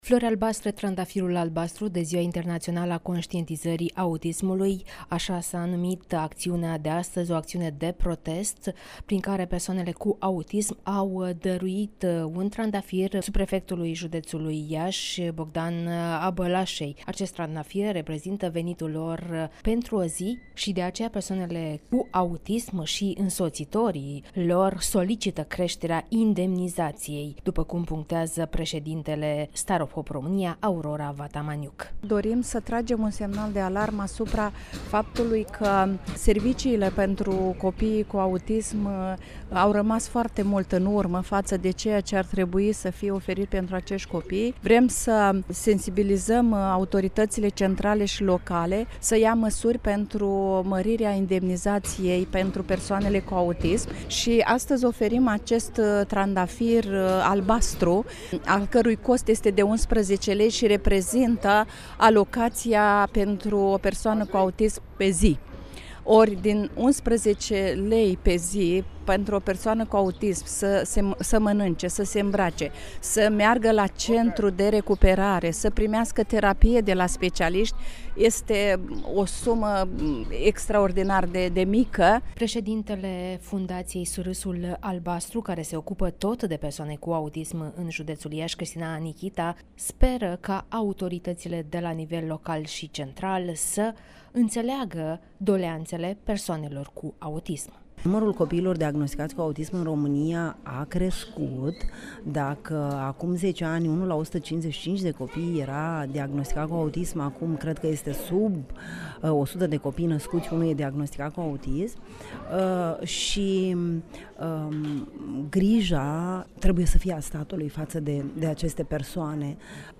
(REPORTAJ) Iași- Protest “Floarea albastră” și Marșul diversității